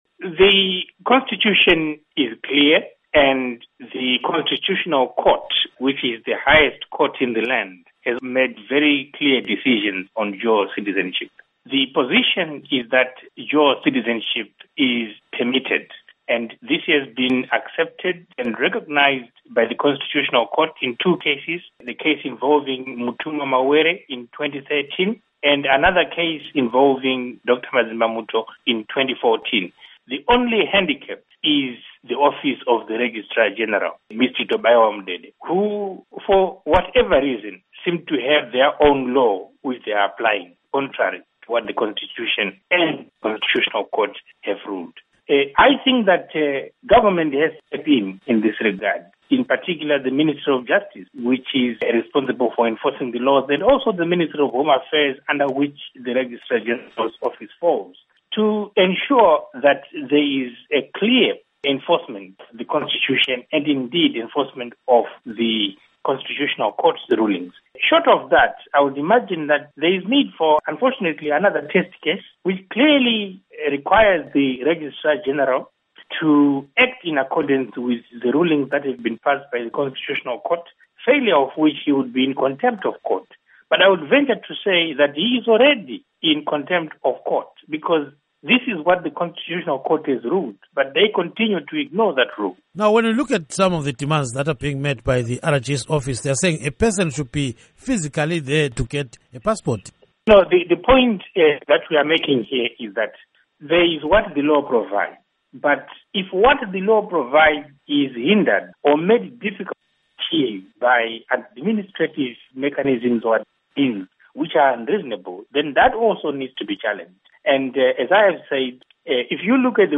Diaspora Forum: Interview